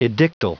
Prononciation du mot edictal en anglais (fichier audio)
Prononciation du mot : edictal